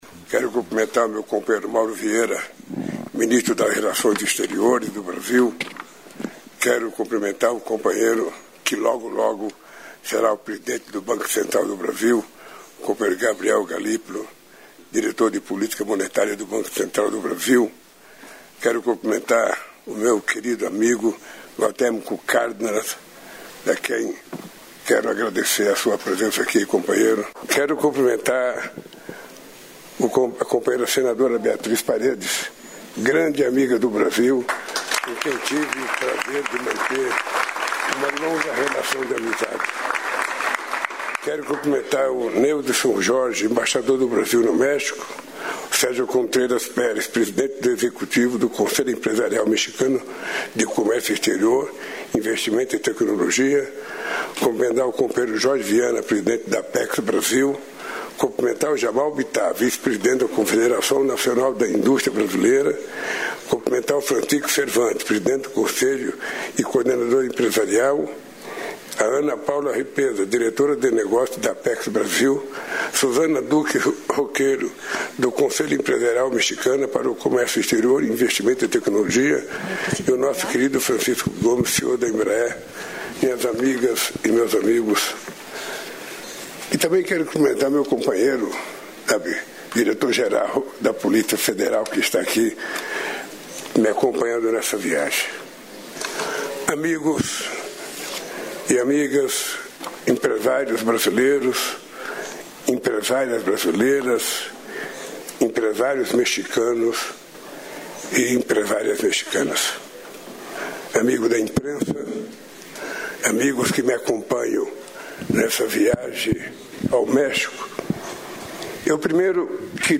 O presidente Luiz Inácio Lula da Silva participou nesta segunda-feira (30) da abertura do Seminário Empresarial México-Brasil, na capital mexicana, e falou a empresários dos dois países; Hoje, Lula também se reúne com a presidente eleita do México, Claudia Sheinbaum.